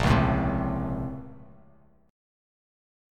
A#mM9 chord